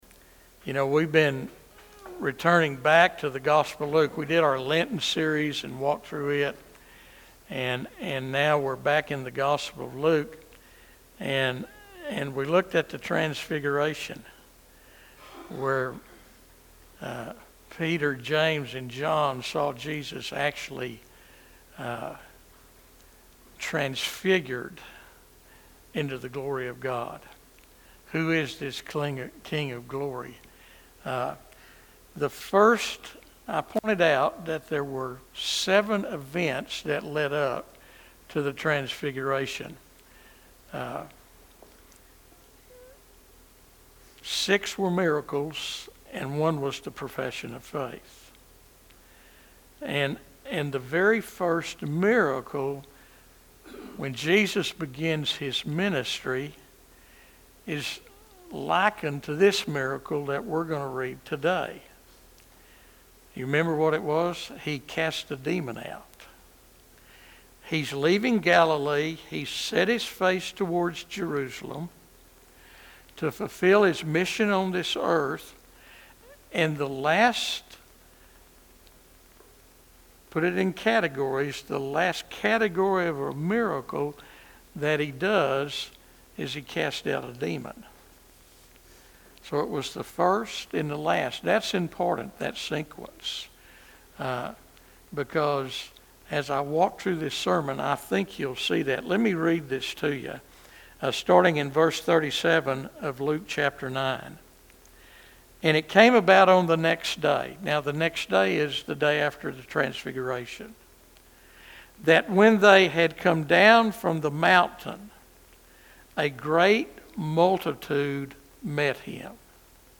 This powerful sermon takes us from Jesus's glorious transfiguration to the harsh reality of a demon-possessed boy and his desperate father.
5.4.25 sermon.mp3